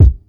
Game_Kick_3.wav